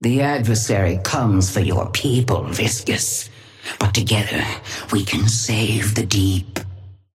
Sapphire Flame voice line - The Adversary comes for your people, Viscous.
Patron_female_ally_viscous_start_06.mp3